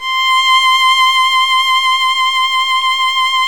Index of /90_sSampleCDs/Roland L-CD702/VOL-1/STR_Violin 1-3vb/STR_Vln2 _ marc
STR  VL C 7.wav